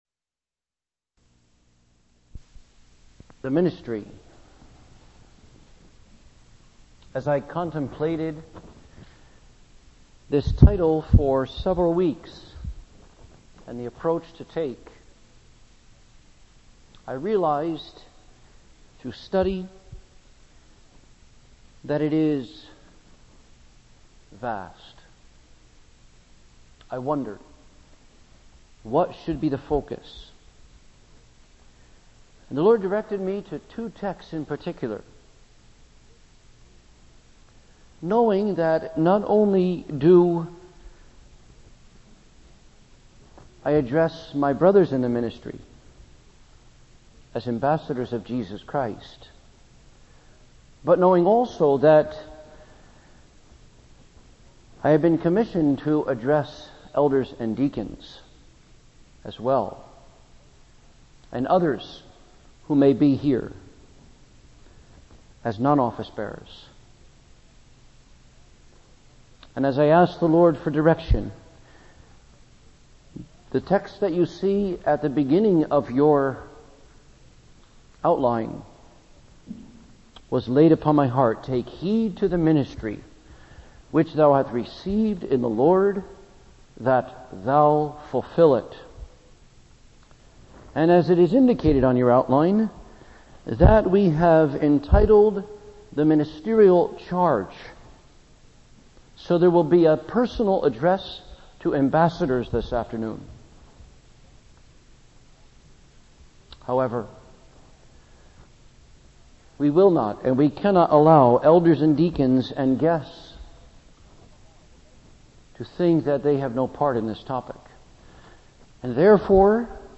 Office Bearer's Conference